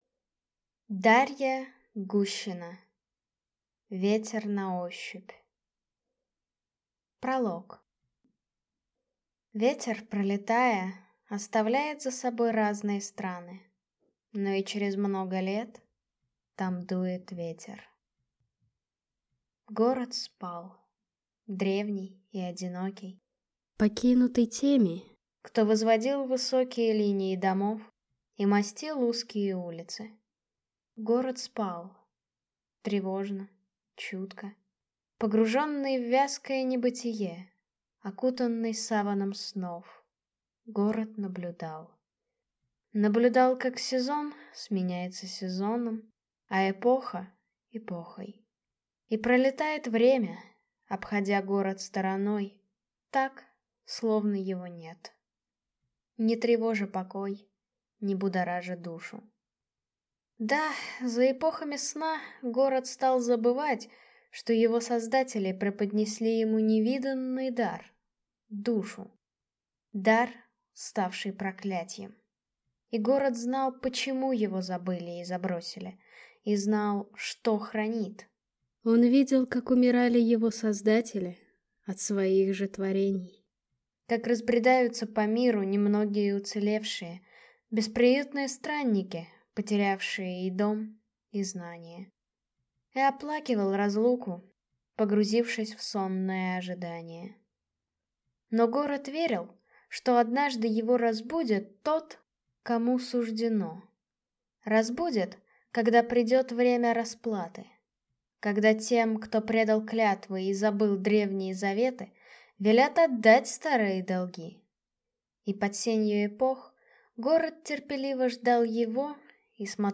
Аудиокнига Ветер на ощупь | Библиотека аудиокниг